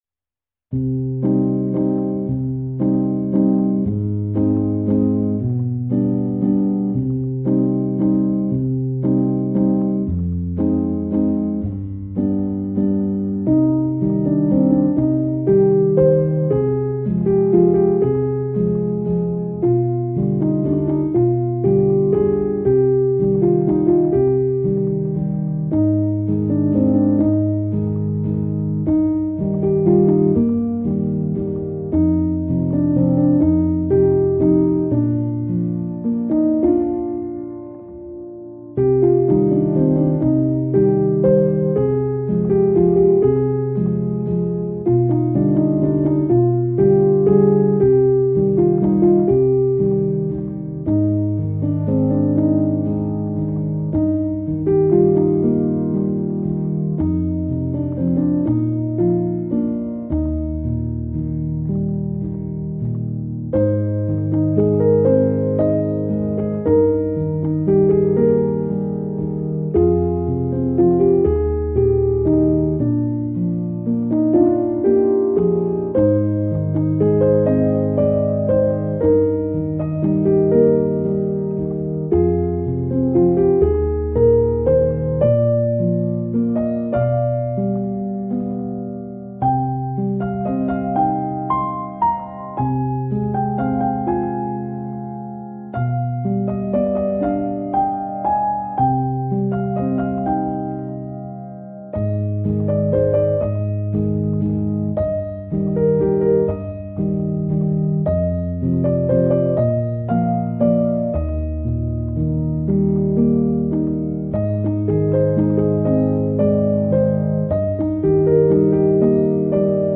سبک آرامش بخش , الهام‌بخش , پیانو , موسیقی بی کلام